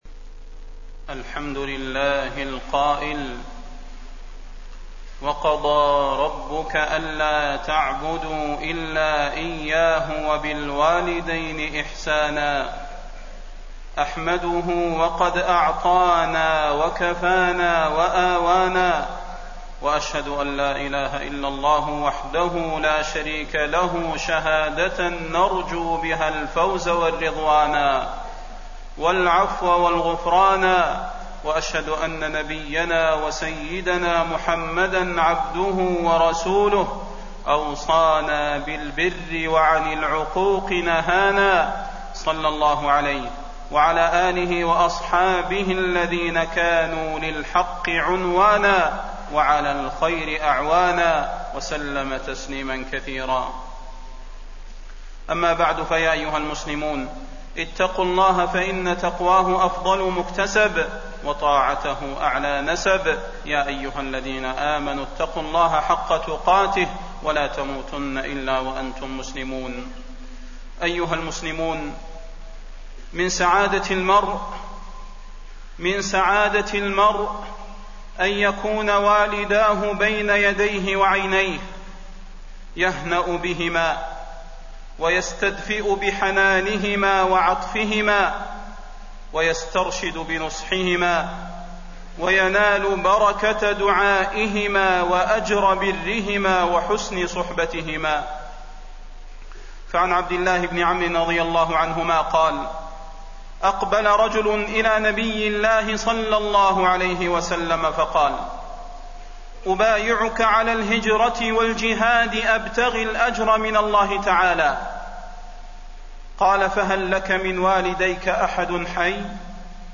تاريخ النشر ١٤ جمادى الآخرة ١٤٣١ هـ المكان: المسجد النبوي الشيخ: فضيلة الشيخ د. صلاح بن محمد البدير فضيلة الشيخ د. صلاح بن محمد البدير بر الوالدين